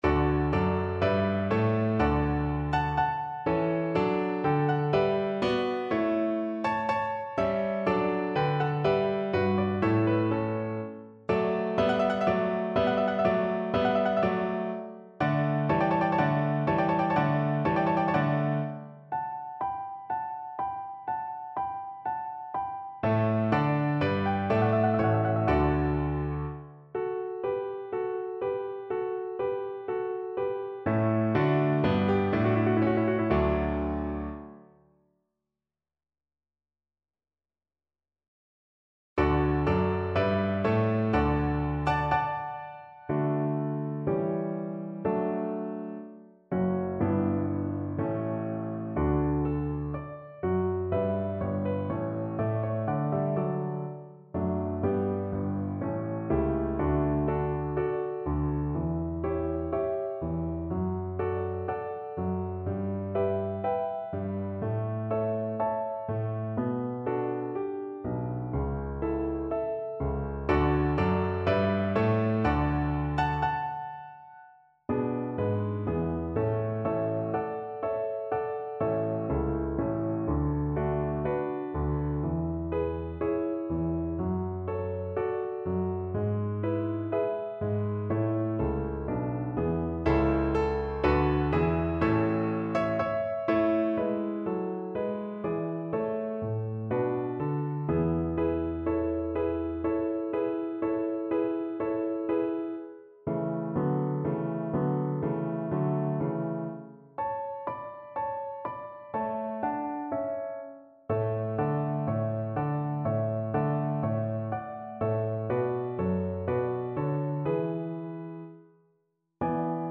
Free Sheet music for Cello
Cello
D major (Sounding Pitch) (View more D major Music for Cello )
Andante (=c.84)
Classical (View more Classical Cello Music)